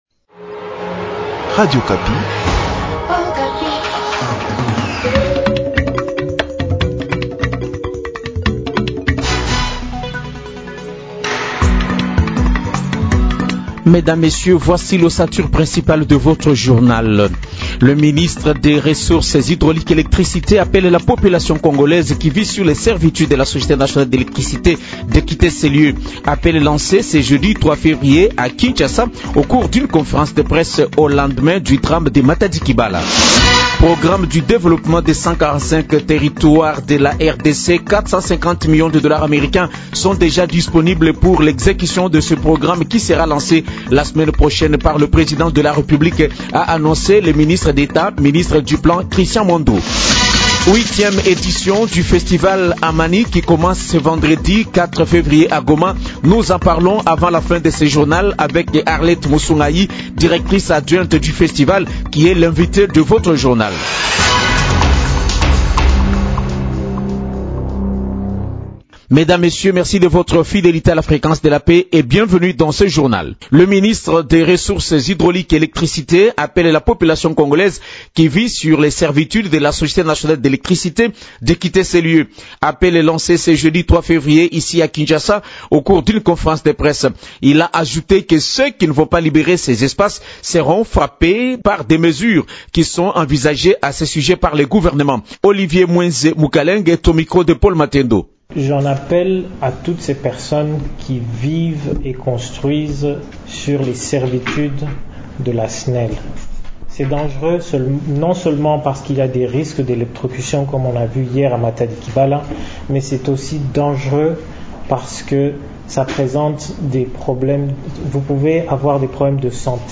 Journal français matin vendredi 4 février 2022